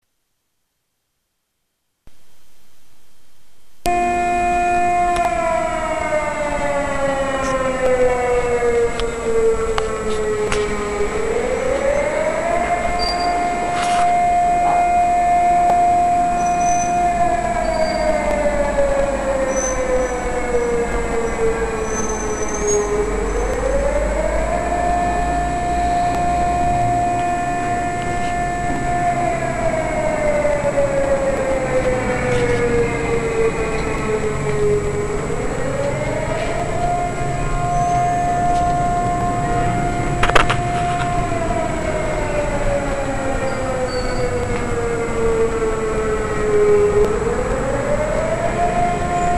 Air defence signal in China